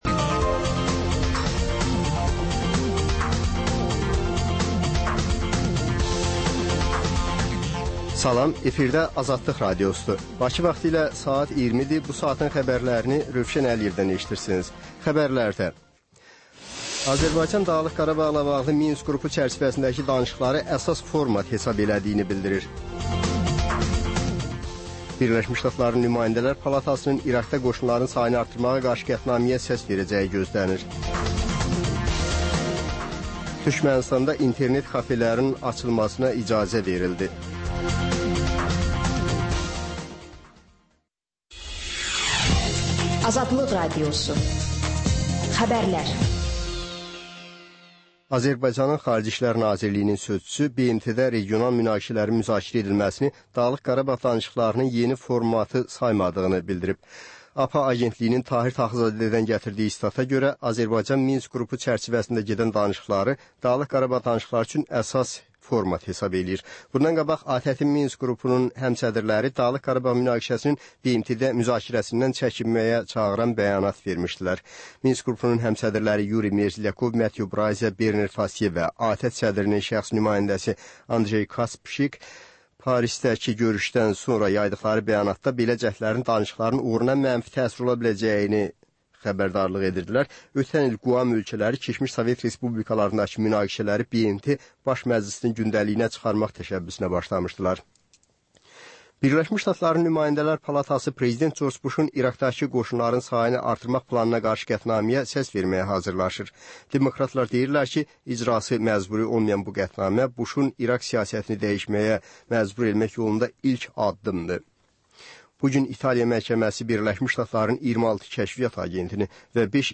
Xəbər, reportaj, müsahibə. Sonra: Günün Söhbəti: Aktual mövzu barədə canlı dəyirmi masa söhbəti.